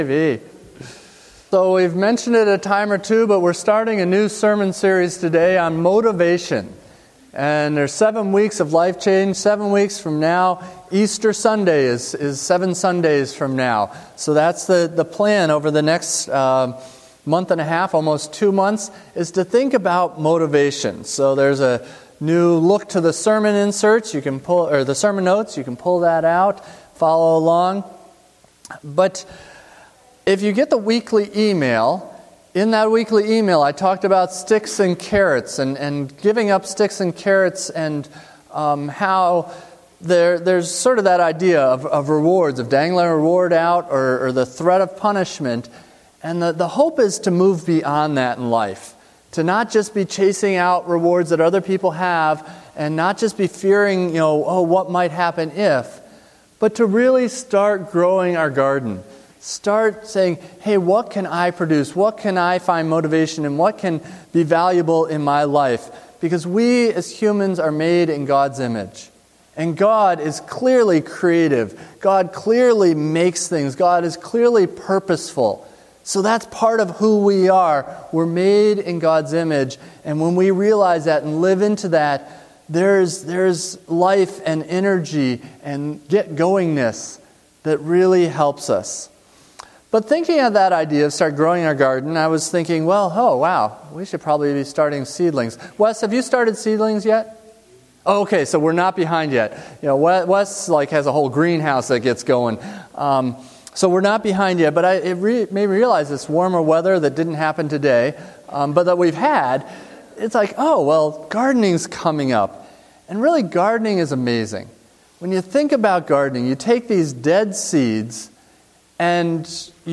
Listen to ERC sermons online.